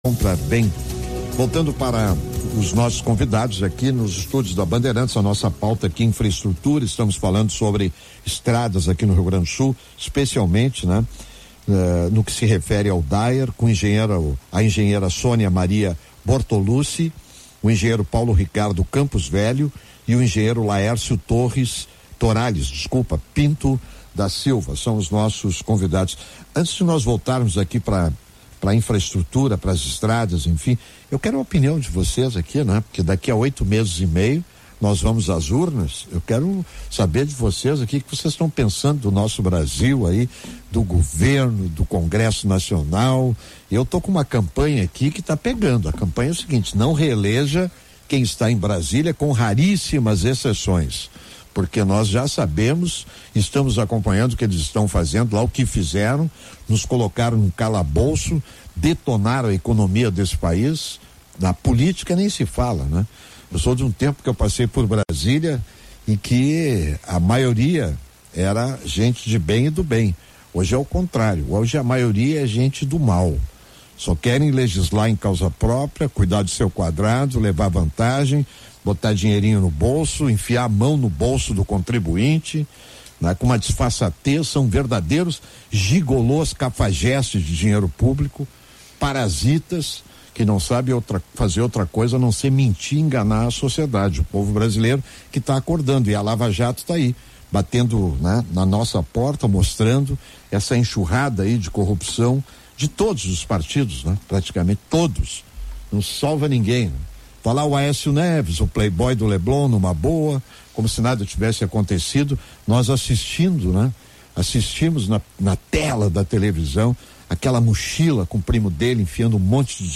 Parte 2 | Debate sobre estradas estaduais